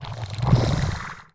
walk_1.wav